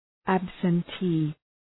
Προφορά
{,æbsən’ti:}